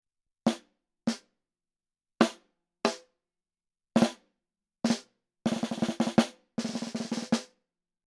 Microphone Shootout – Snare Drum Edition
In order to eliminate as many variables as we could, the microphones were set up in as close to the same spot as we could get and I played every test the same way: Center hit, off-center hit, flam, nine-stroke roll.
We had two of almost all of these microphones, so the snare drum is double-miked for all but a few tests (which I’ll disclose when we get to them) – one microphone on the top head, the other on the bottom placed right around the snares themselves.
The first microphone listed is panned hard left and the second is hard right.
Next we have the Audio Technica battle – ATM29 (discontinued) vs. ATM63 (also discontinued).
snare-mic-shootout-3.mp3